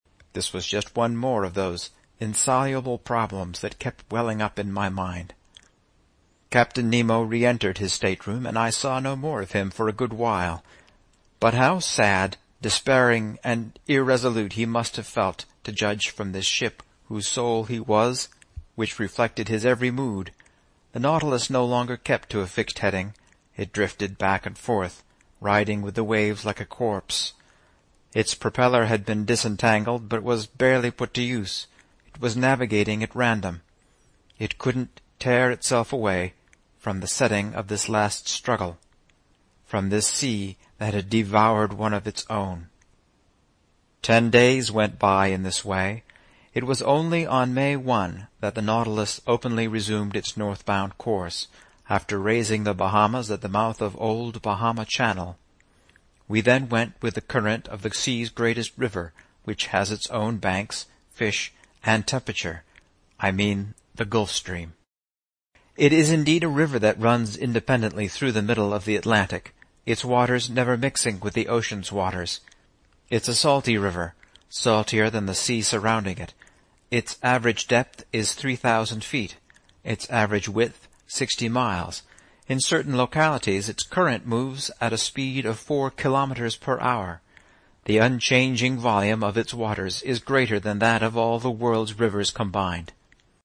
在线英语听力室英语听书《海底两万里》第511期 第32章 海湾暖流(2)的听力文件下载,《海底两万里》中英双语有声读物附MP3下载